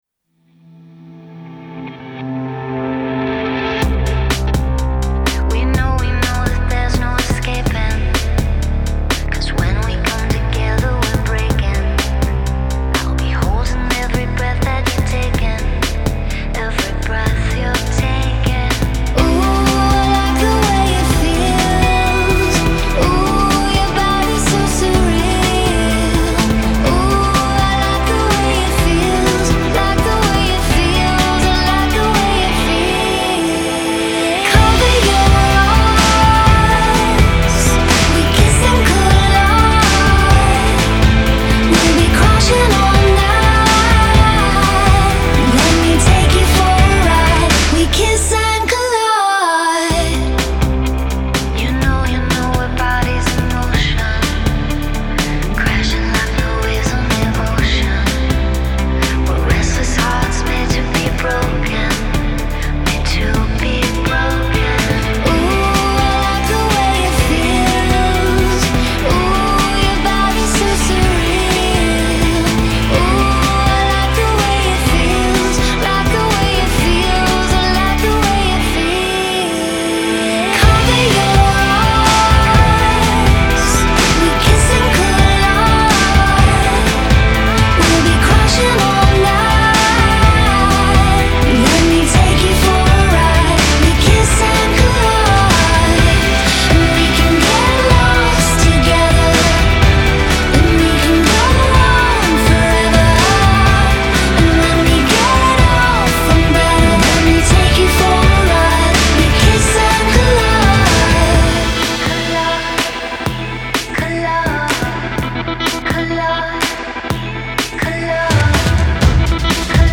Genre: Indie Pop